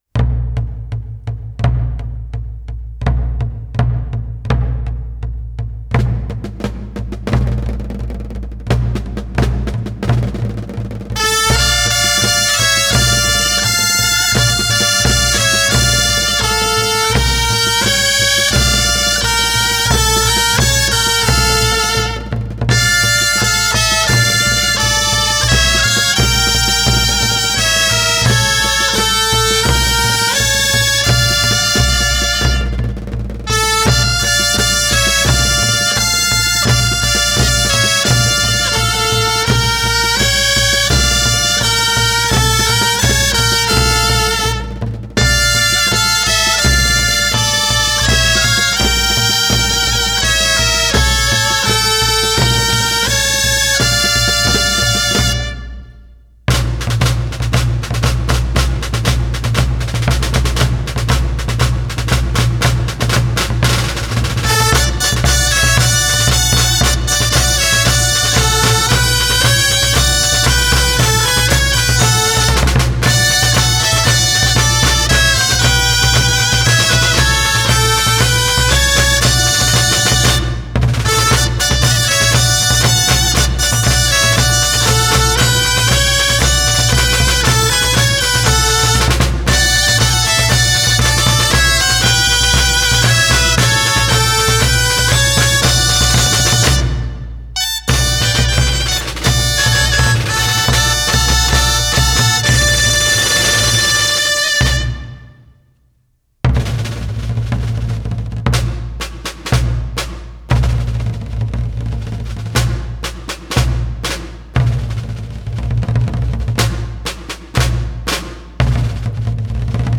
Santa Tecla Tarragona